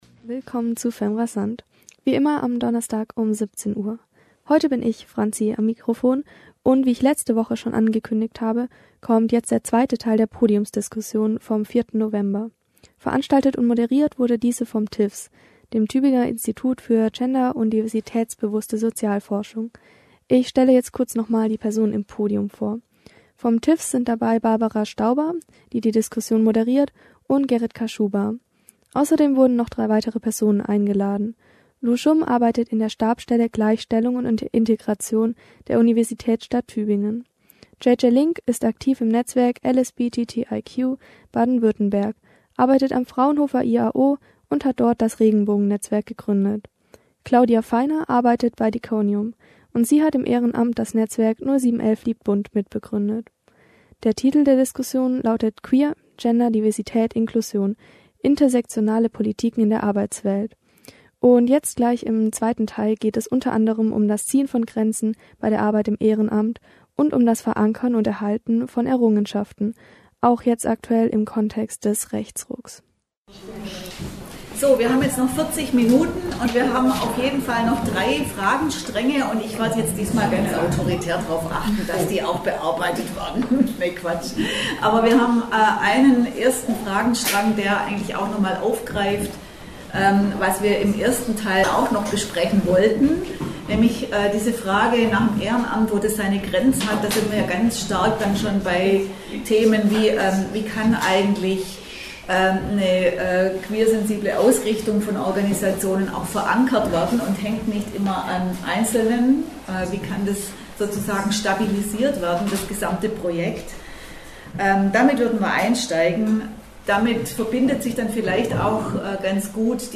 am 4.11. fand im Brahmsaal der Museumsgesellschaft Tübingen eine Podiumsdisskusion zu intersektionalen Politiken in der Arbeitswelt statt, fem*rasant war live dabei